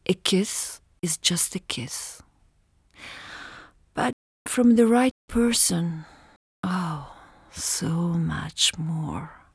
kiss.wav